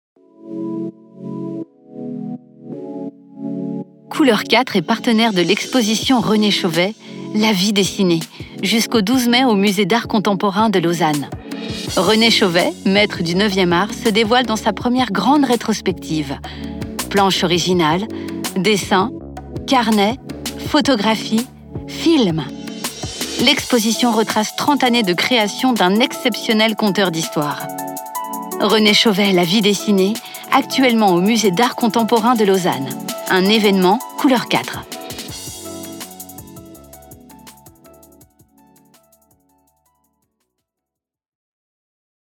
Voix-off 1